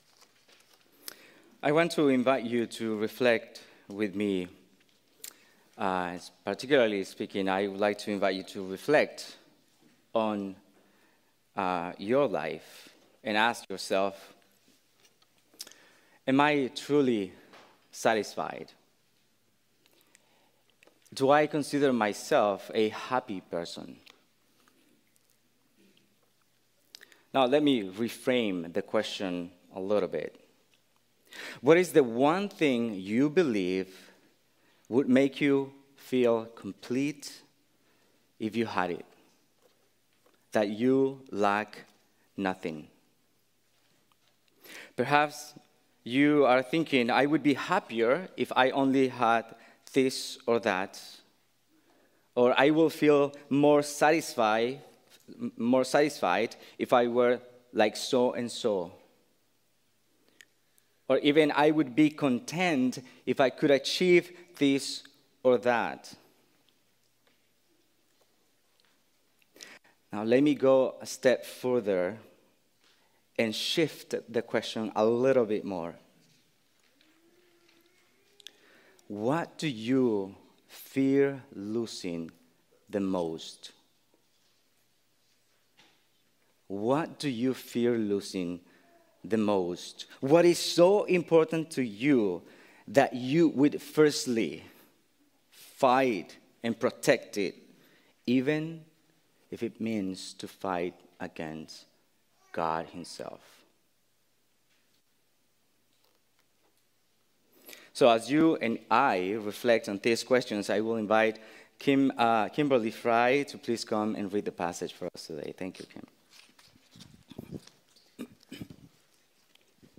Sermons preached at Iron Works Church.